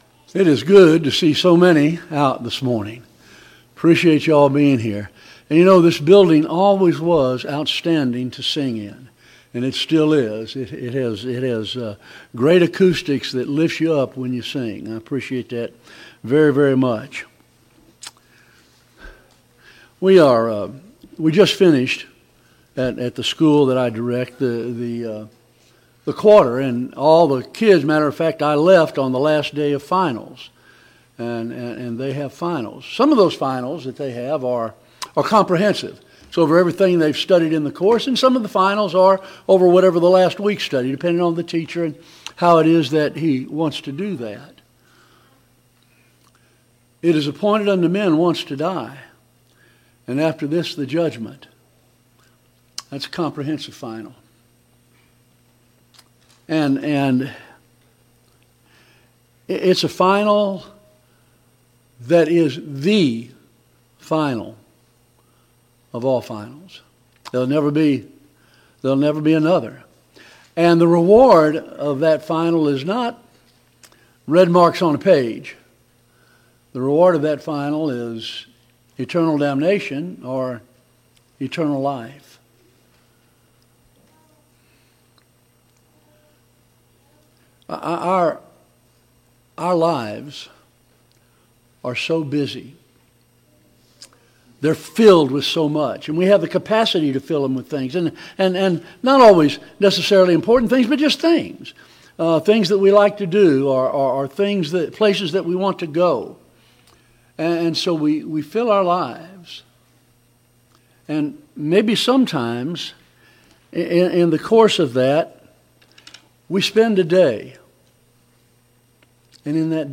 2025 Fall Gospel Meeting Service Type: Gospel Meeting « 3.